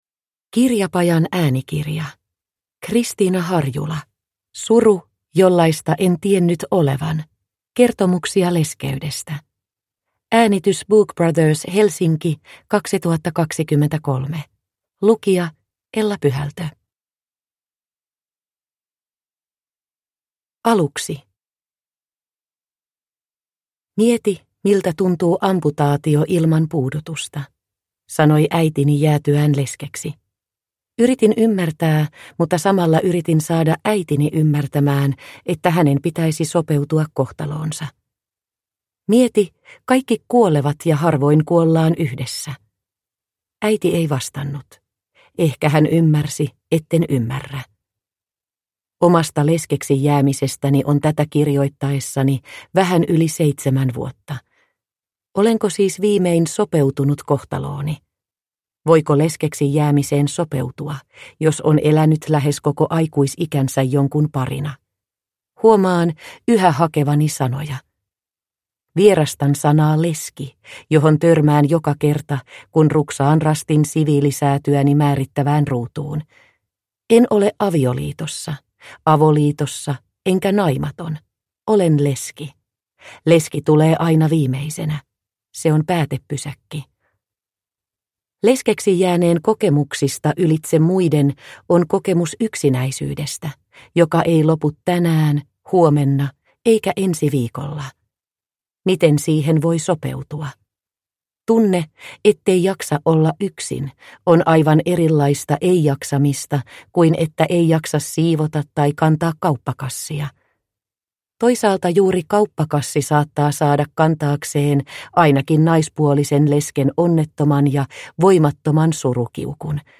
Suru, jollaista en tiennyt olevan – Ljudbok – Laddas ner